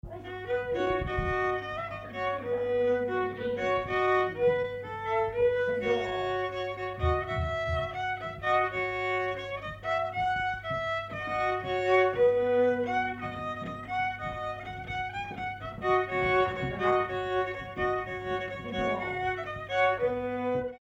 Air
Pièce musicale inédite